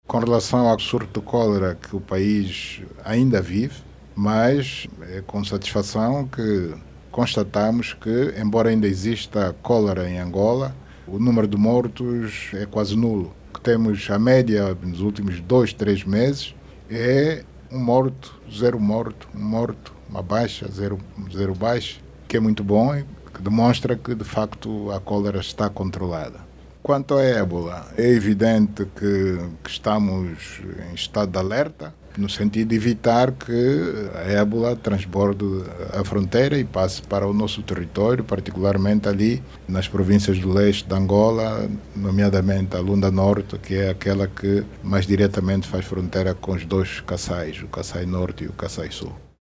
A garantia foi dada no final da cerimónia de inauguração do Complexo Hospitalar Pedro Maria Tonha “Pedalé”.
Em conferência de imprensa, o Chefe de Estado afirmou que estão a ser tomadas todas as medidas necessárias para impedir que a doença atravesse as fronteiras e chegue ao território nacional.